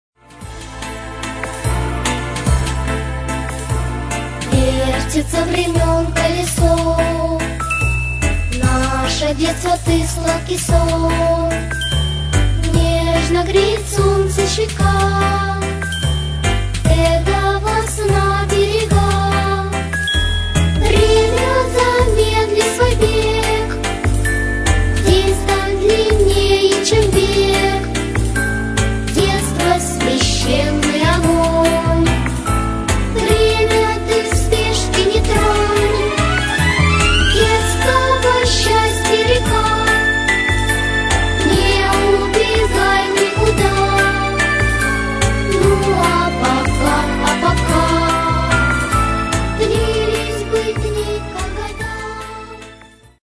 редкий размер 5/4